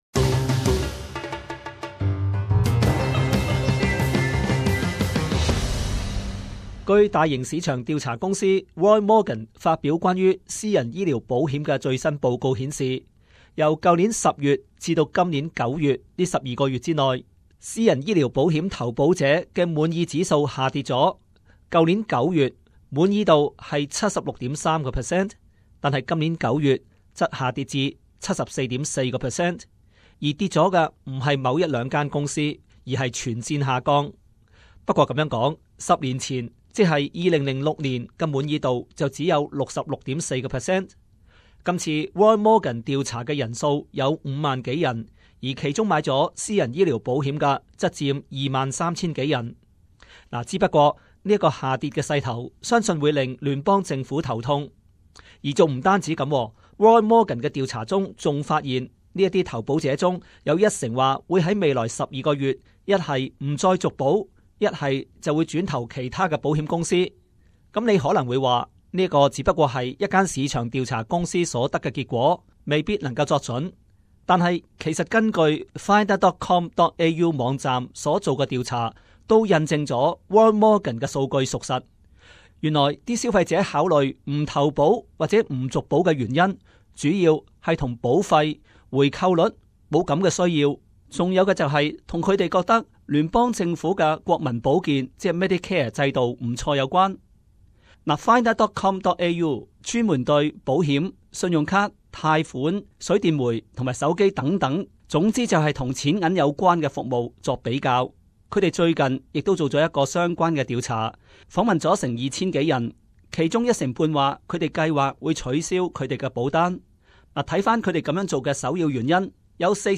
【時事報導】 爲何市民對私人醫療保險公司滿意度下降？